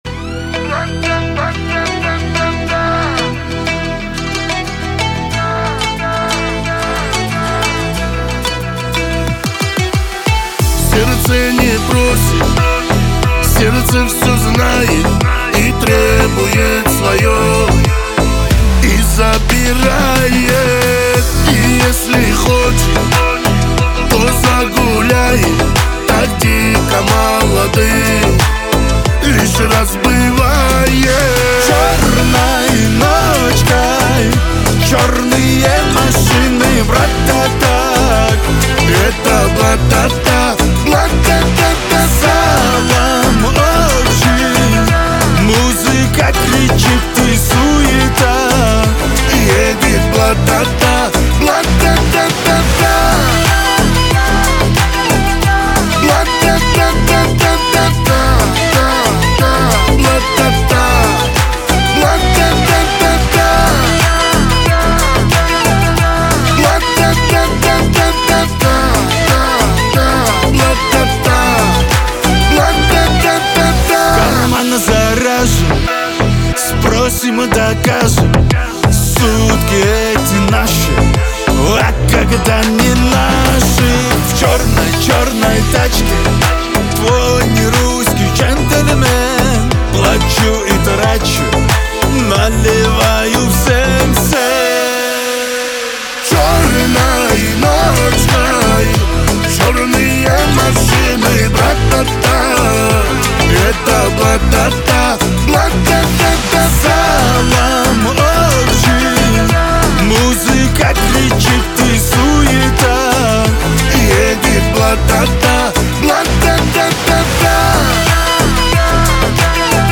Кавказская музыка